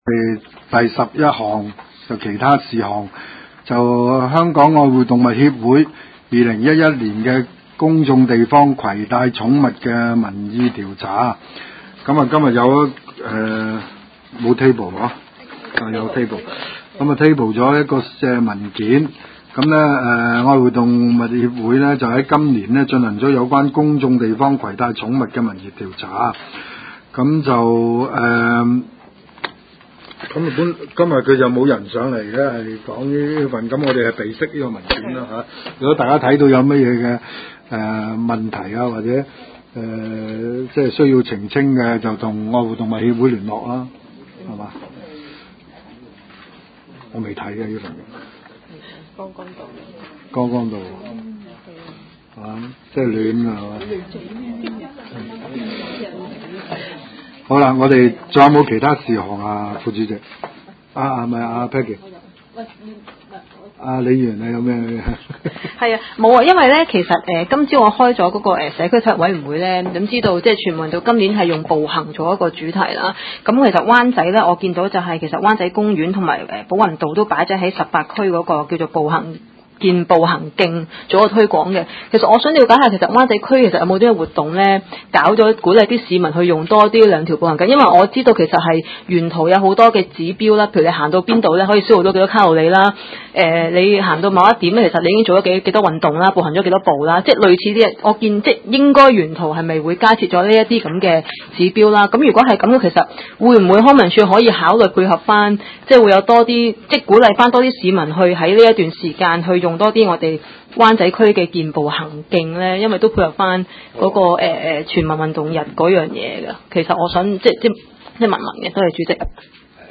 文化及康體事務委員會第二十三次會議
灣仔民政事務處區議會會議室